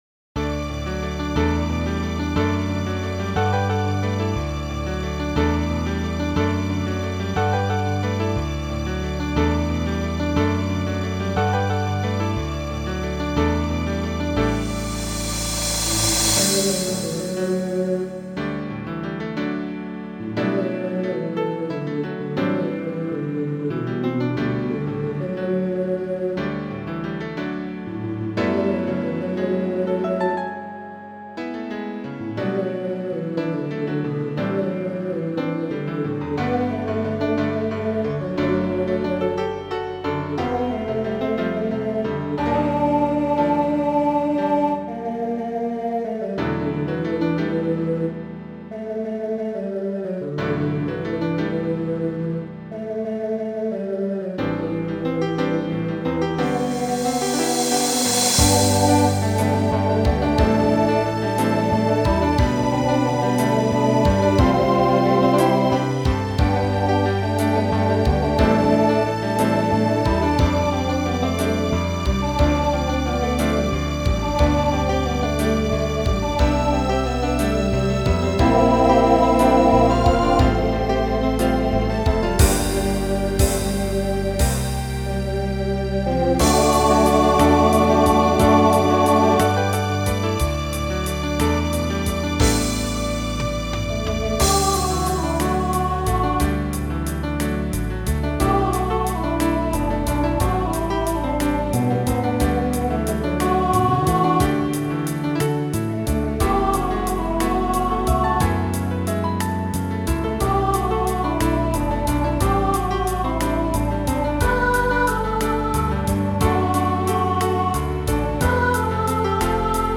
Voicing SATB Instrumental combo Genre Broadway/Film
Ballad